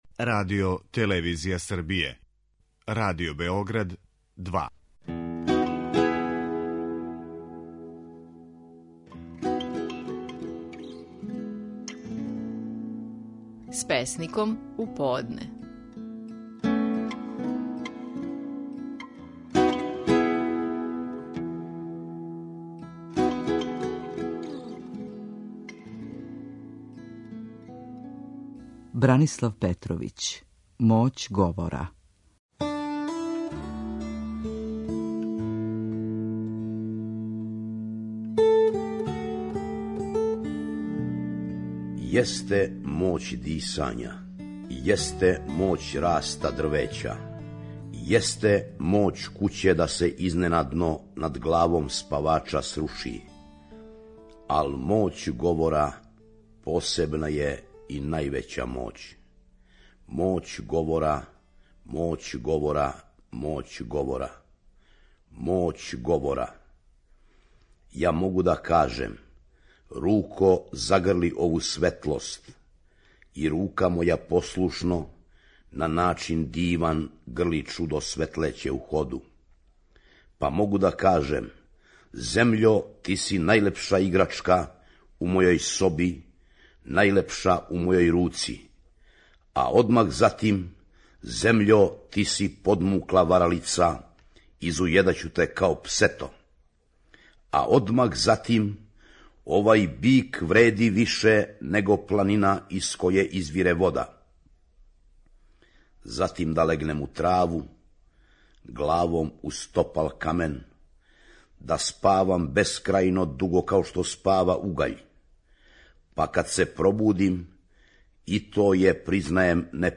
Наши најпознатији песници говоре своје стихове
У данашњој емисији, можете чути како је своју песму Моћ говора казивао Бранислав Петровић.